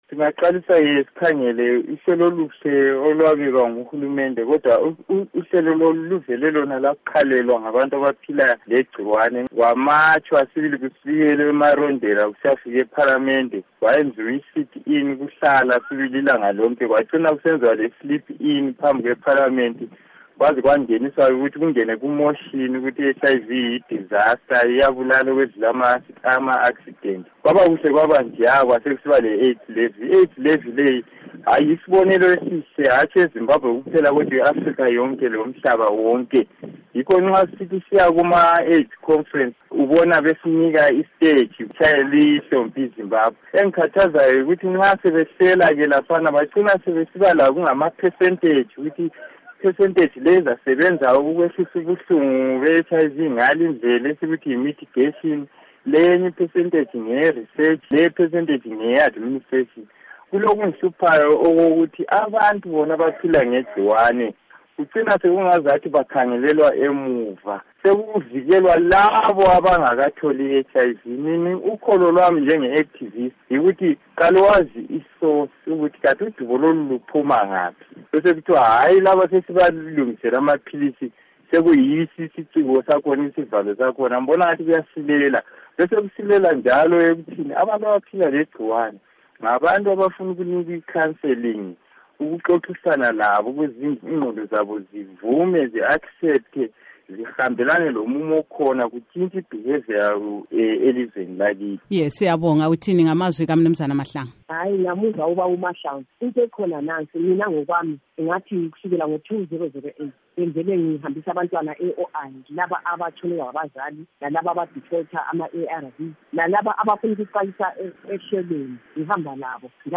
Ingxoxo LoKhansila Monica Lubimbi Endawonye LoMnu.